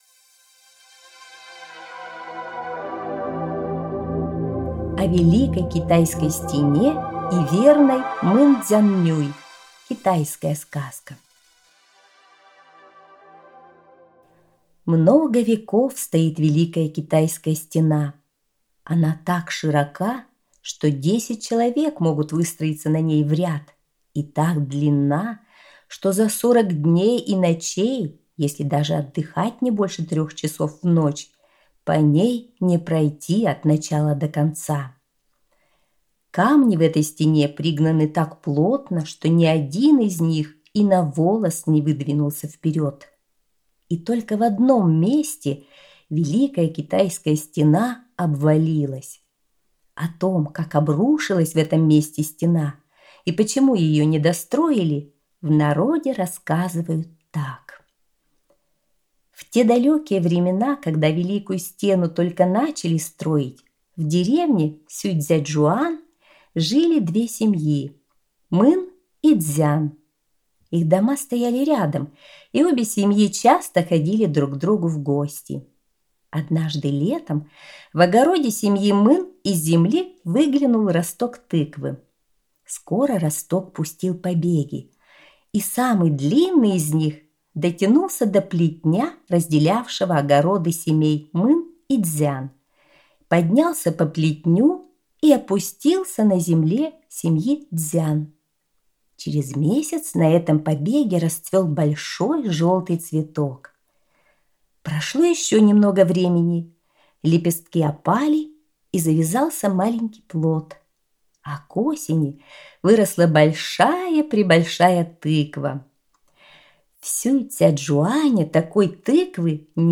О Великой Китайской стене и верной Мын Цзян-нюй - китайская аудиосказка